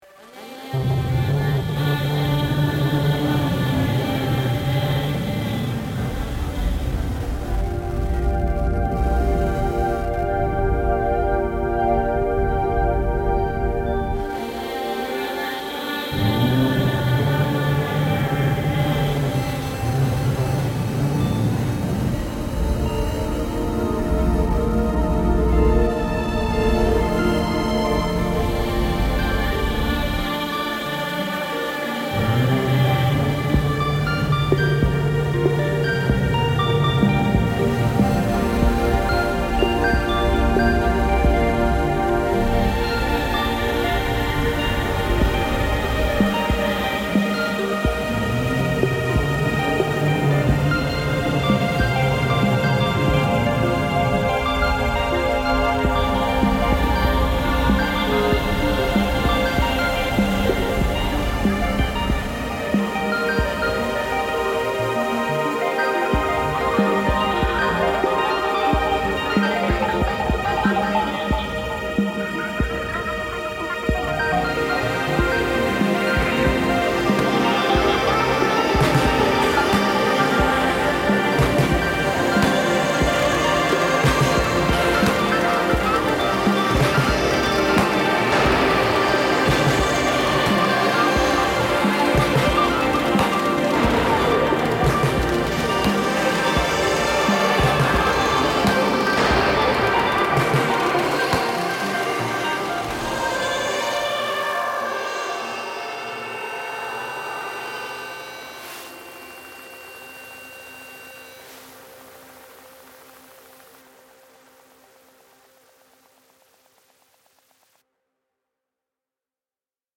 The source material that I worked with was a field recording from the 60s in which someone in Morocco is singing a song, begging for bread.
All of that influenced the atmosphere of this short track, and my decision to take the original recording and bury it amidst liminal-sounding distorted “call holding” music: even as our societies have ostensibly developed and evolved since the original recording was made - with technology and infrastructures becoming infinitely more complex - the same problems and tragic inequities continue on.